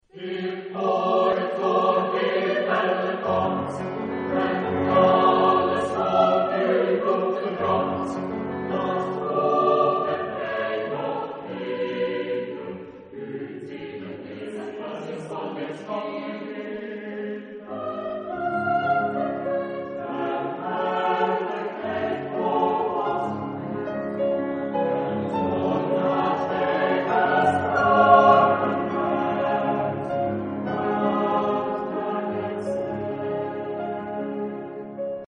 Genre-Style-Form: Canticle ; Sacred ; Romantic
Type of Choir: SATB  (4 mixed voices )
Instruments: Piano (1)
Tonality: F sharp minor